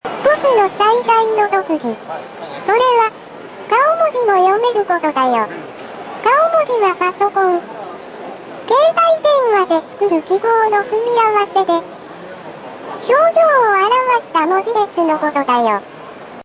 パソコンにUSBでつなぐとメールを読み上げてくれるというフクロウ。